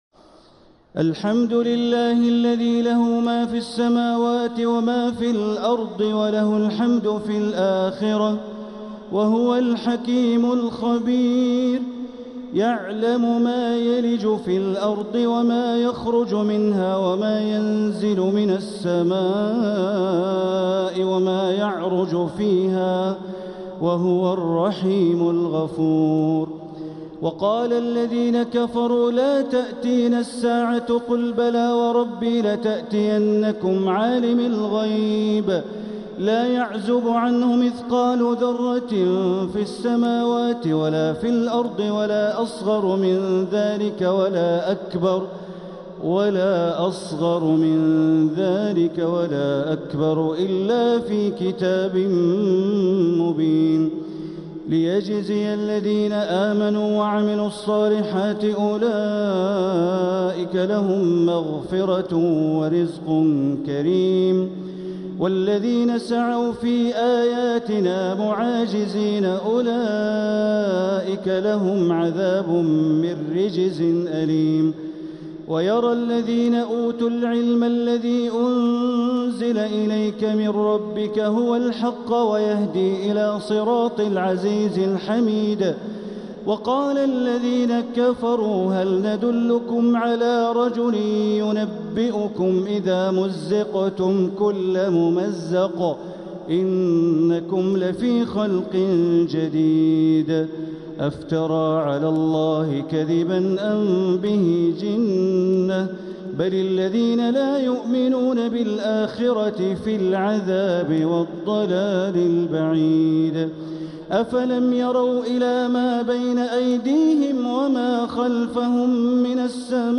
سورة سبأ | مصحف تراويح الحرم المكي عام 1446هـ > مصحف تراويح الحرم المكي عام 1446هـ > المصحف - تلاوات الحرمين